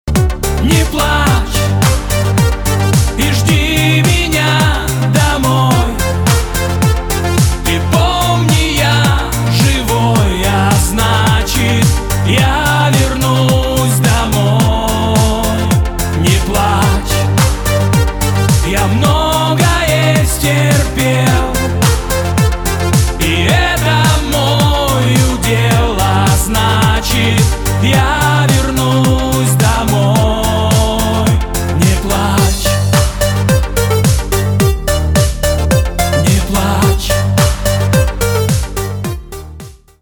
рингтоны шансон